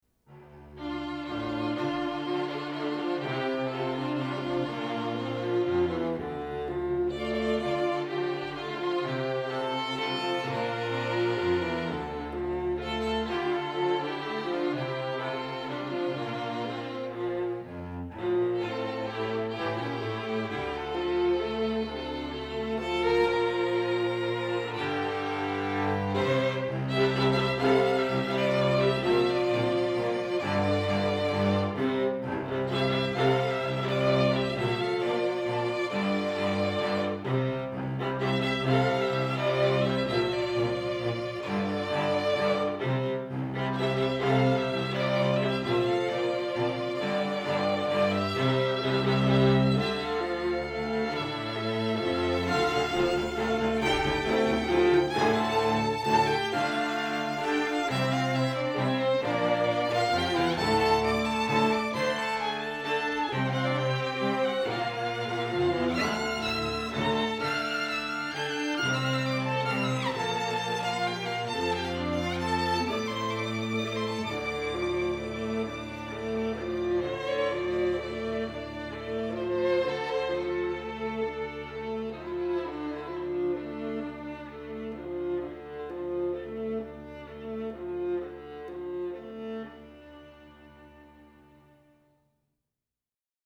Quatuor
Rock